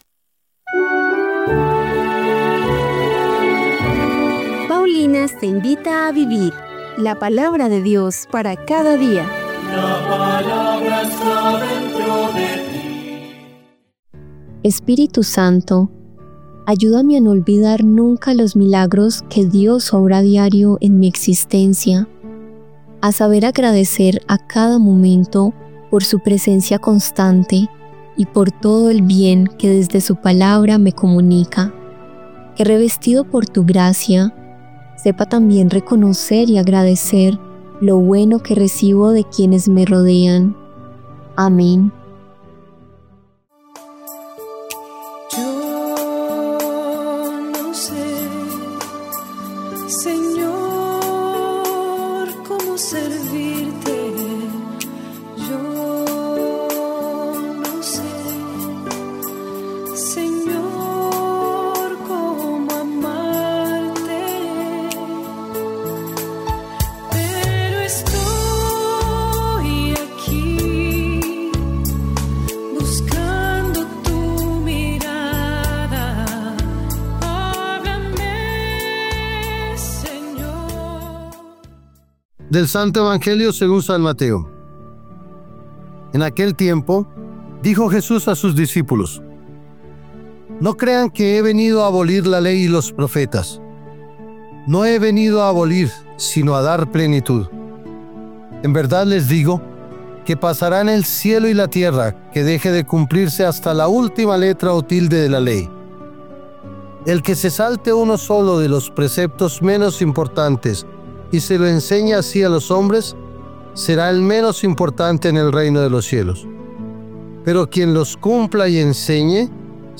Lectura del libro de Isaías 49, 1-6